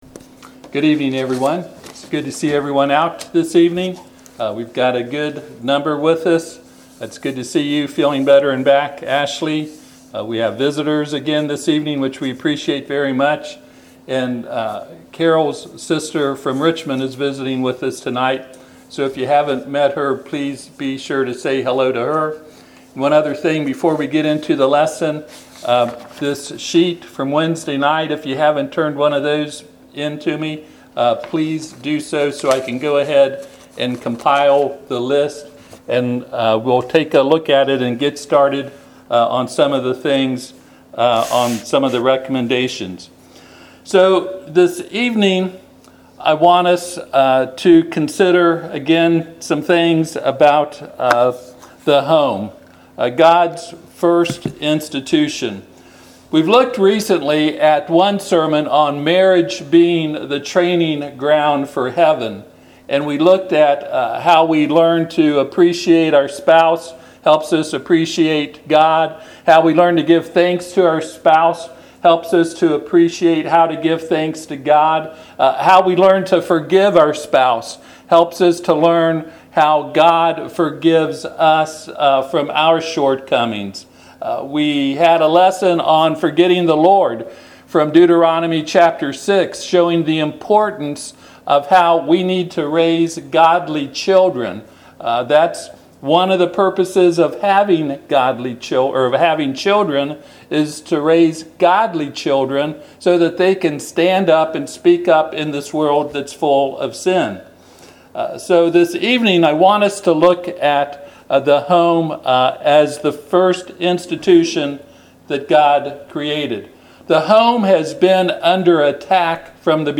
Passage: Genesis 2:18-24 Service Type: Sunday PM